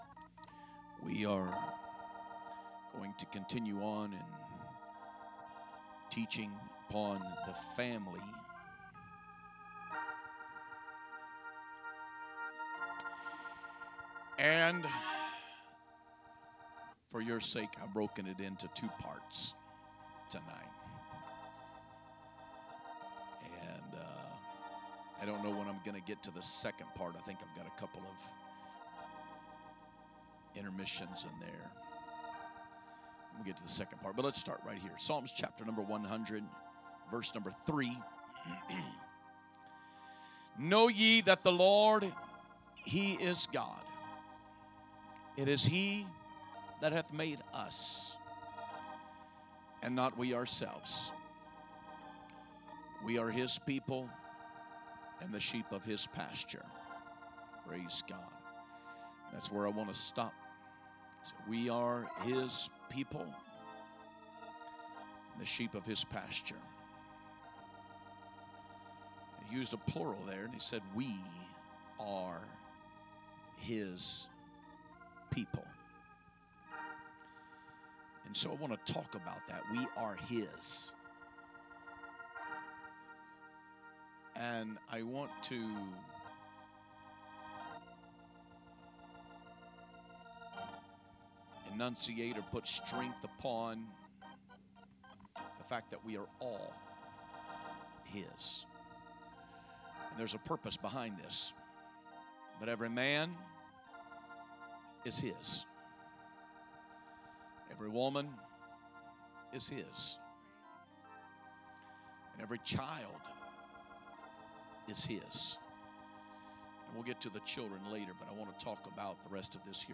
Sunday Evening Message - Lesson 2.1 We Are His